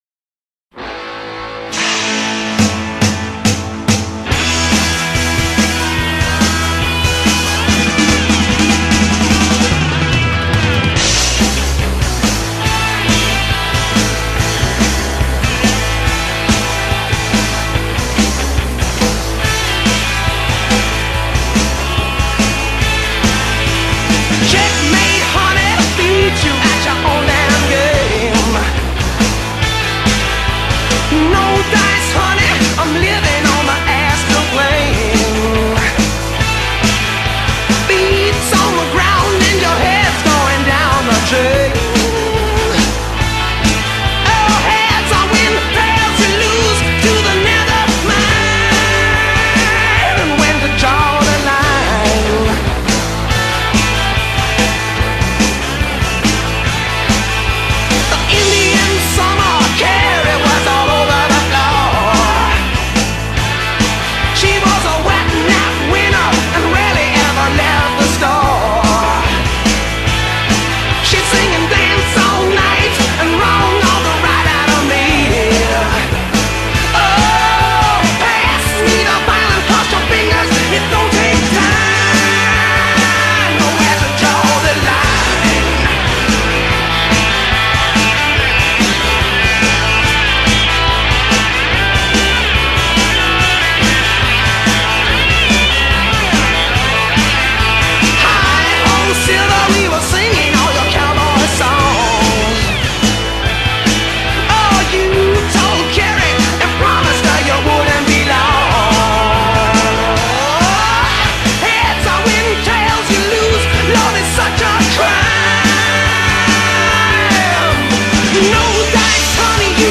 classic rock